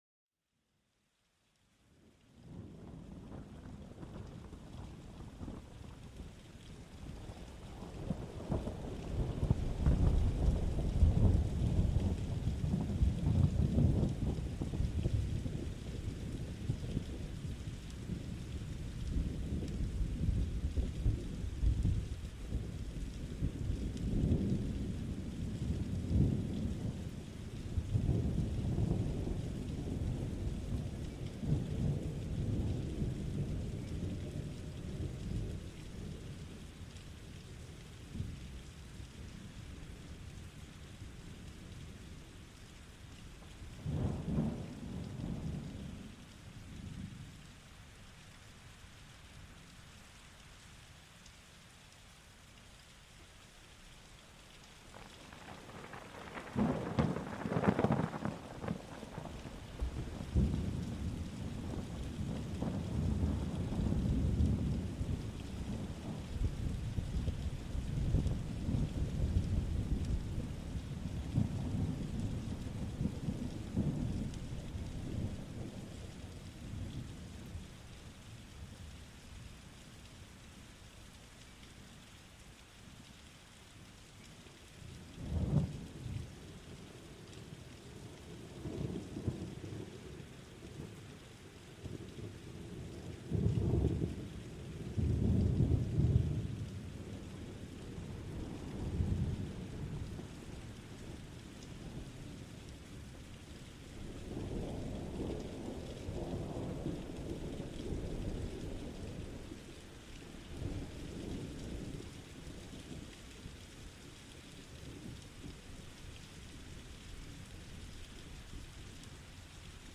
Plongez au cœur de l'orage, où le grondement du tonnerre et les éclairs illuminent le ciel, une véritable manifestation de la puissance de la nature, cet épisode vous invite à expérimenter l'intensité et la majesté d'un orage, un spectacle sonore et visuel qui fascine et inspire, laissez-vous transporter par l'énergie électrisante de l'orage, un moment où le ciel s'exprime dans toute sa splendeur.Ce podcast est une expérience audio immersive qui plonge les auditeurs dans les merveilleux sons de la nature.